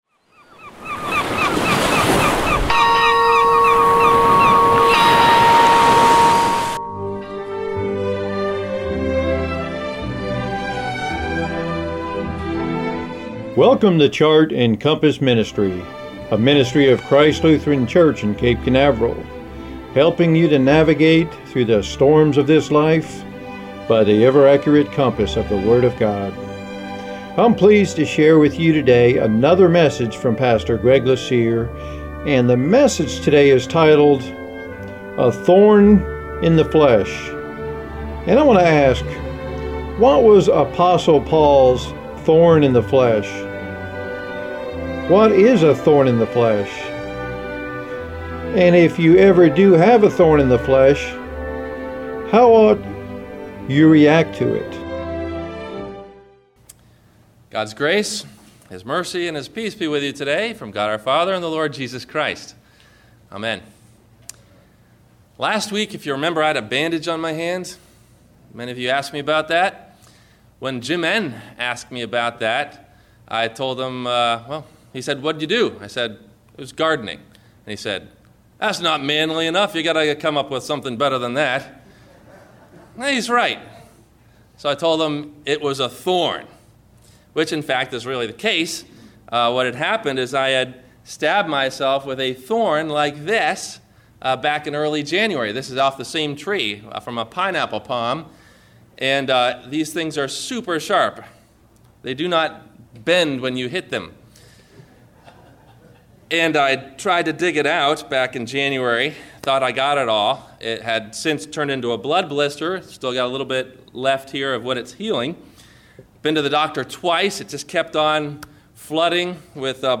A Thorn in the Flesh – WMIE Radio Sermon – December 07 2015